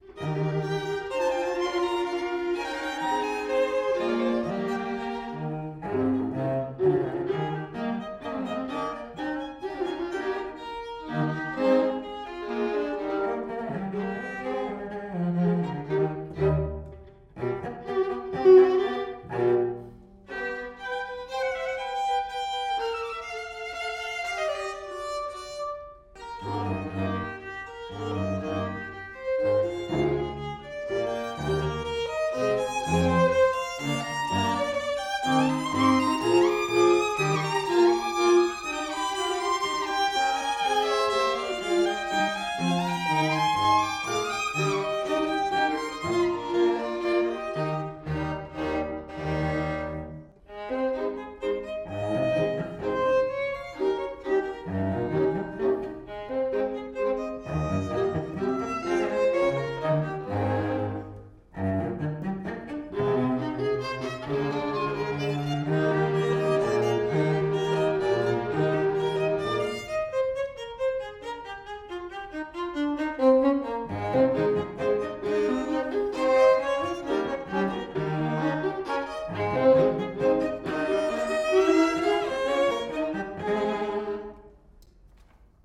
Next comes a Menuetto in the French style.